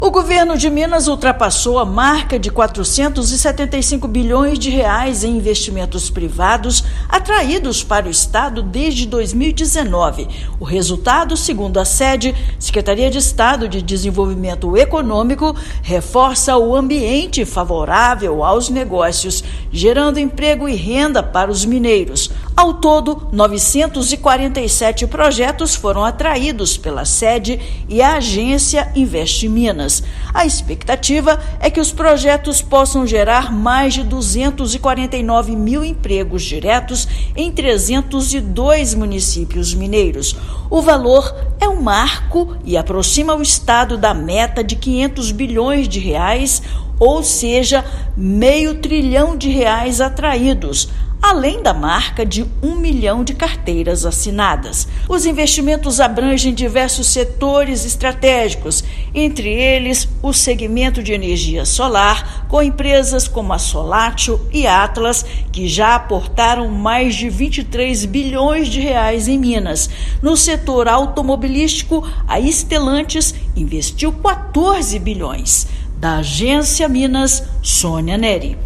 Os 947 projetos atraídos devem gerar mais de 249 mil empregos diretos para os mineiros. Ouça matéria de rádio.